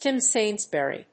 ティム・セインズベリー